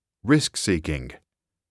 [risk] [seek-ing]